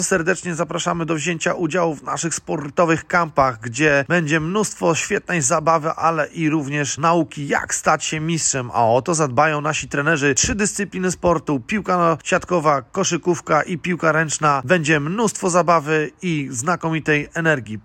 – Mówi Krzysztof Ignaczak, były libero siatkarskiej reprezentacji Polski i jeden z trenerów, którzy będą brali udział w akcji.